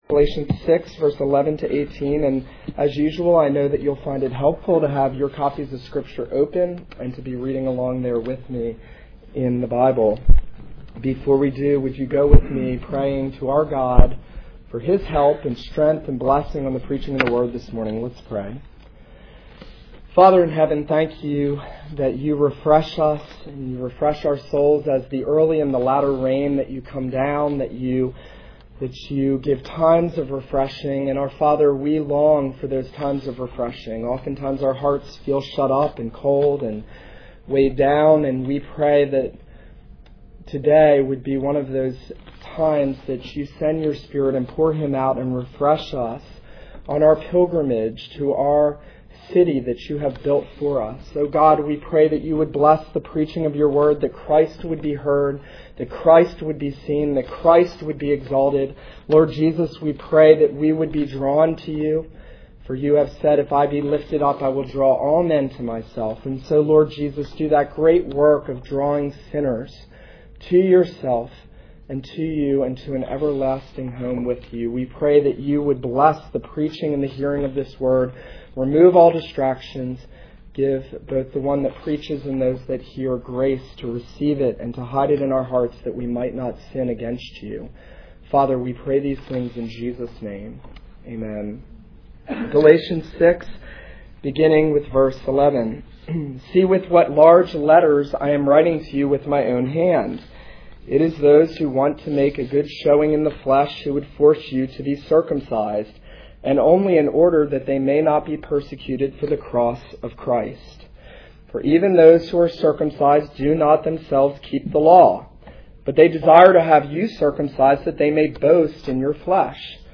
This is a sermon on Galatians 6:11-18.